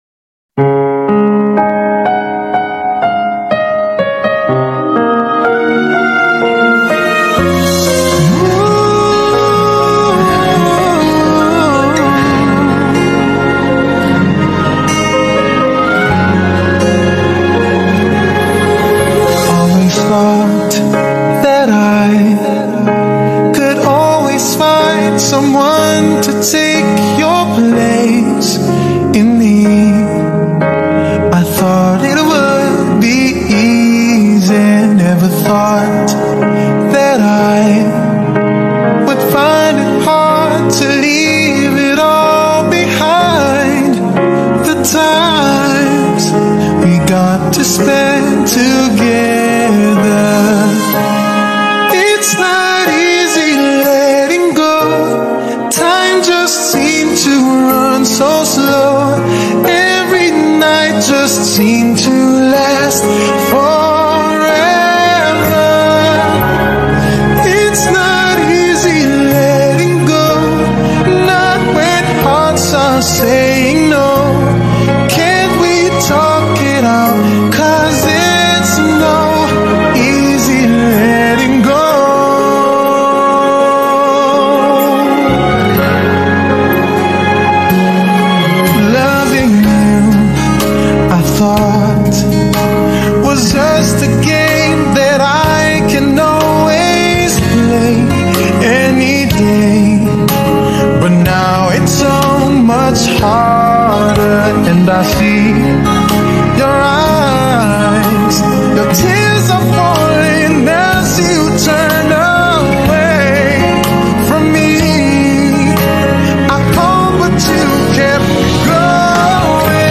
heartfelt ballad